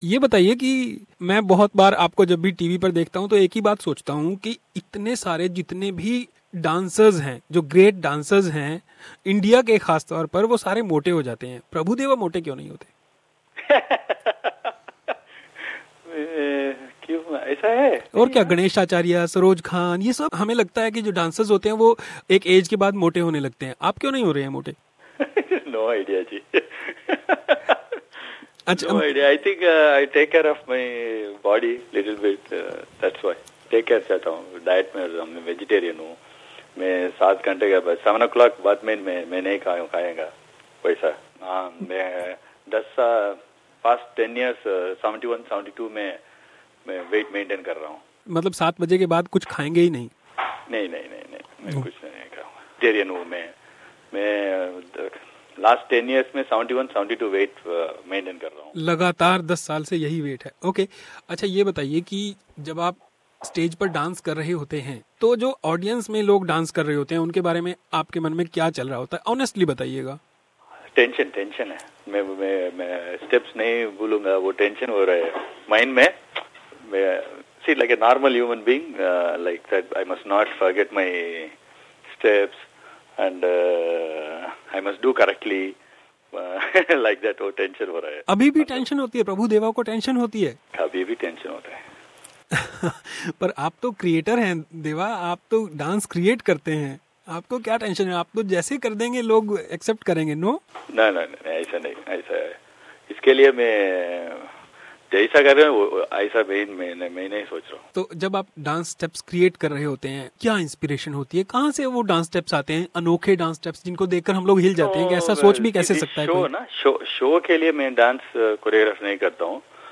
One of the best dancers of Indian cinema, Prabhu Deva will be in Sydney for Dabang Da Tour show. He talked exclusively to SBS and shared his moments of happiness and fear.